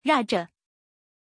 Aussprache von Raja
pronunciation-raja-zh.mp3